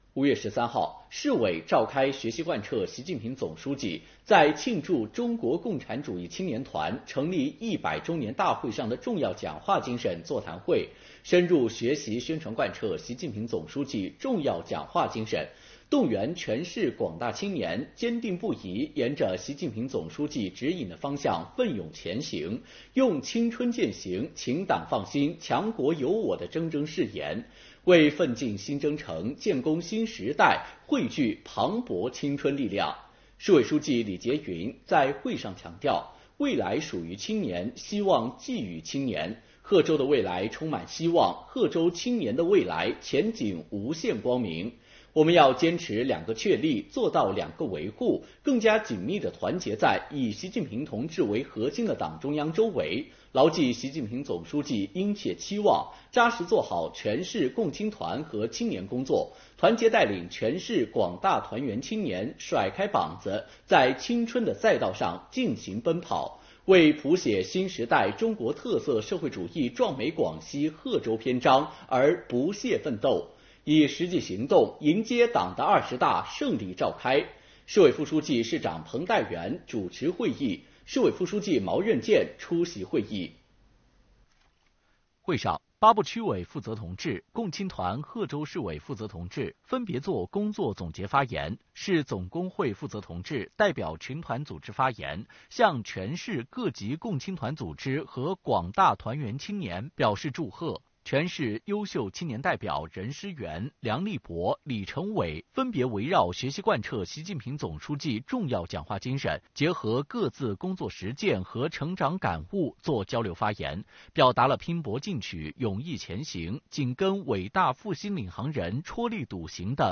李杰云讲话 彭代元主持
贺州市委书记李杰云在会上讲话。
贺州市委副书记、市长彭代元主持会议。